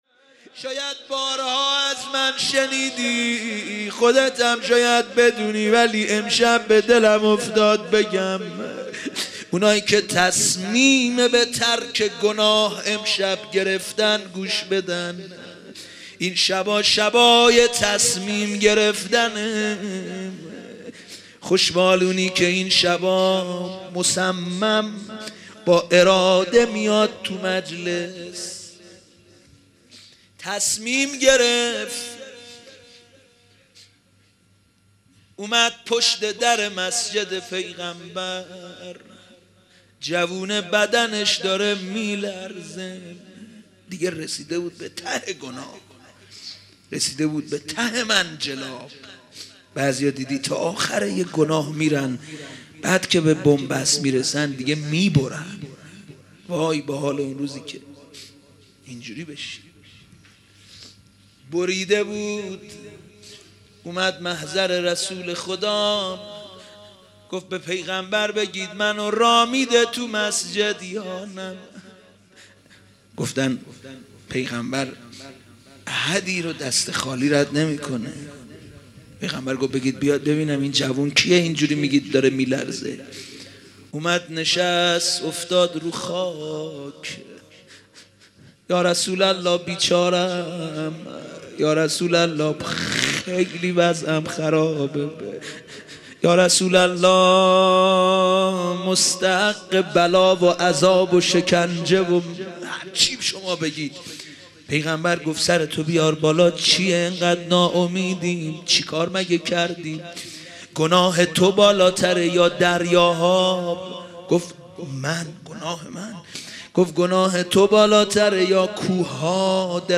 روضه
روضه مناسبت : شب نوزدهم رمضان - شب قدر اول سال انتشار